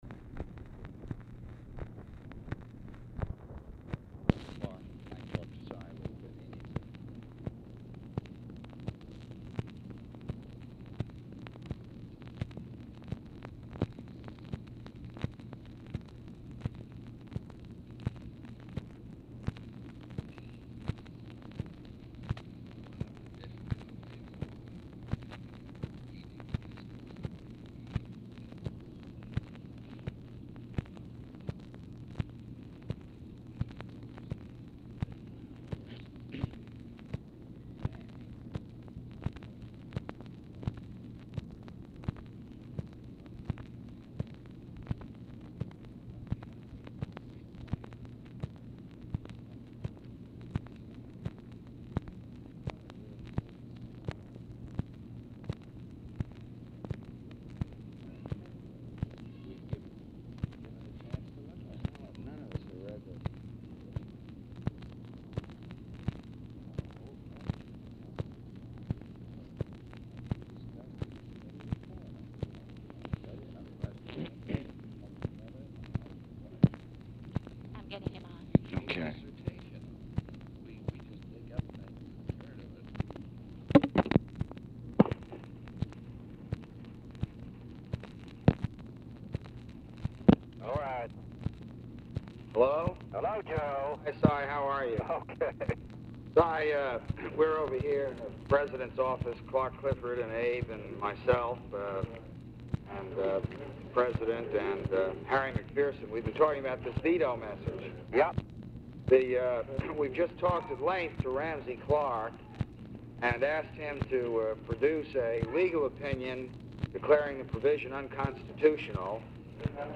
Telephone conversation # 8585, sound recording, LBJ and CYRUS VANCE, 8/20/1965, 10:01PM | Discover LBJ